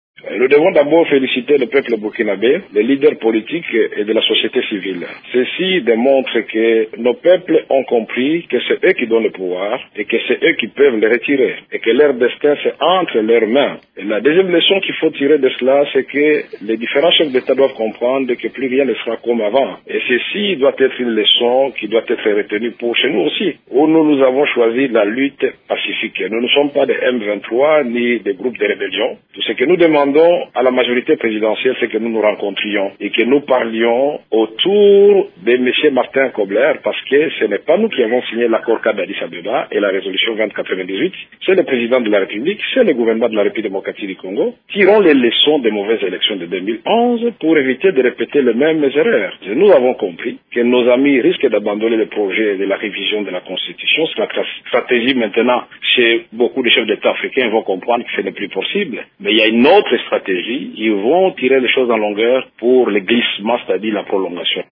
Lors d’une conférence de presse vendredi, l’opposant Kamerhe a félicité le peuple, les leaders politiques et ceux de la société civile burkinabè pour le changement survenu dans leur pays.